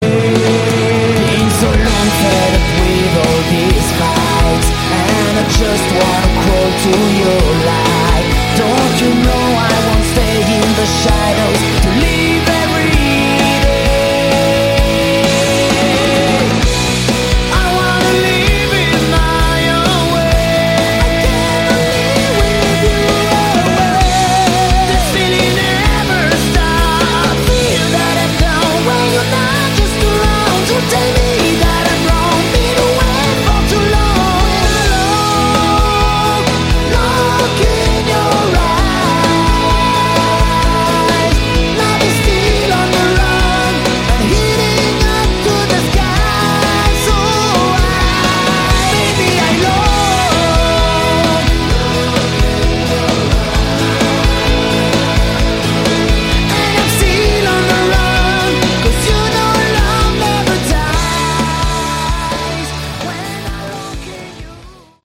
Category: Hard Rock
guitar
keyboards
lead vocals
bass
drums